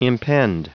Prononciation du mot impend en anglais (fichier audio)
Prononciation du mot : impend